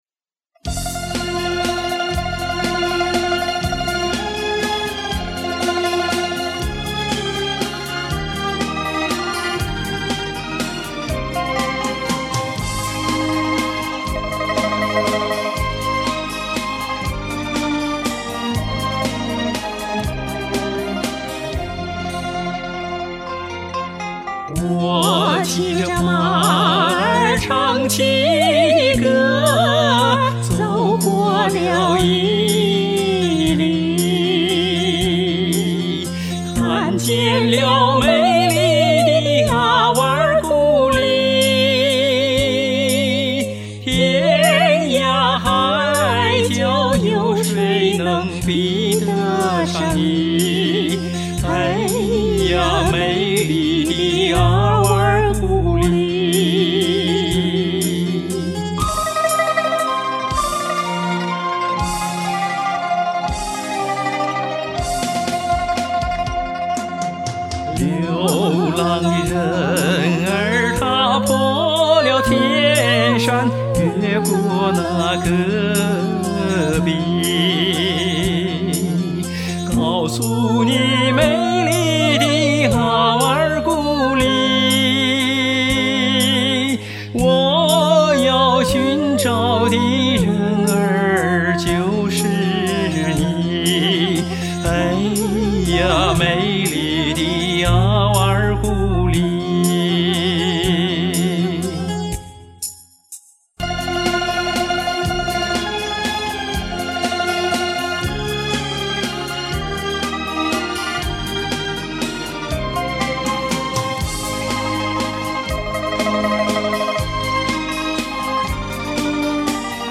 新疆維吾爾族民歌《阿瓦爾古麗